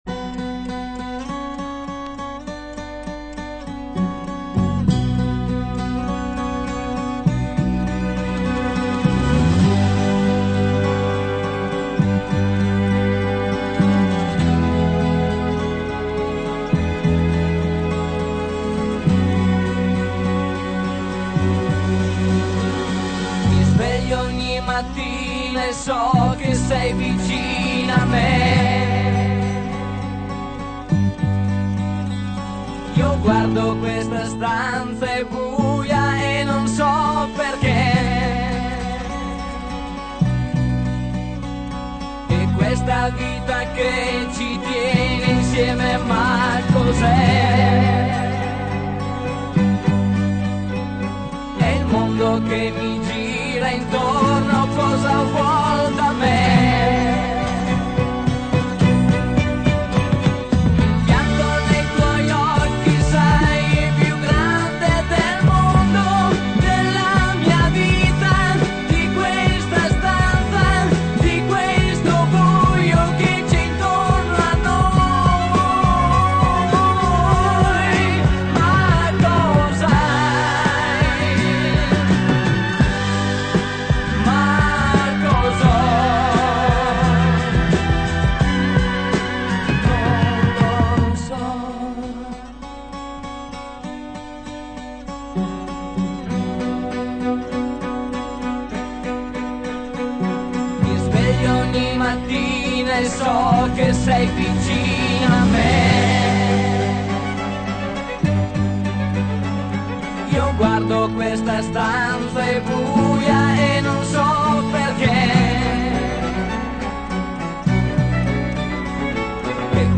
(Provini realizzati in sala prove)